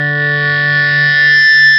Index of /90_sSampleCDs/E-MU Producer Series Vol. 2 – More Studio Essentials/Composer/Plunge Guitars
FBACK D2.wav